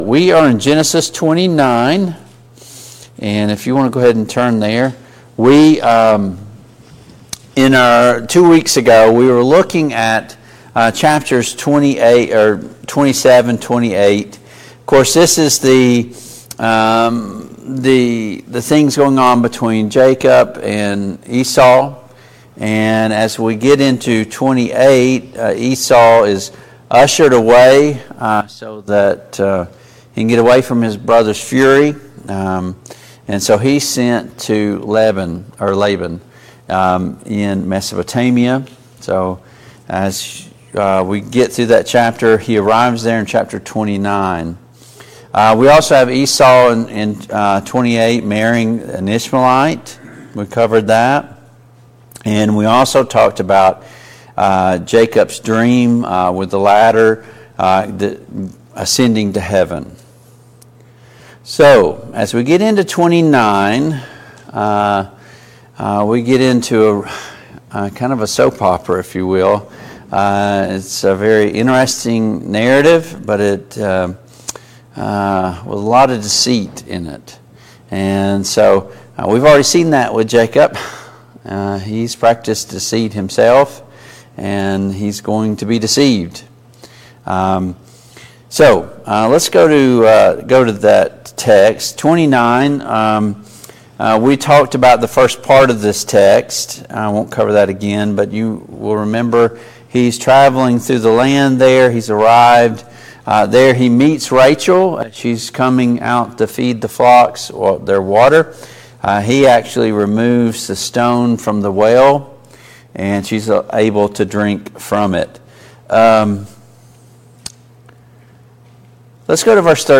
Study of Genesis Passage: Genesis 29, Genesis 30 Service Type: Family Bible Hour « The Christian Life is the blessed life!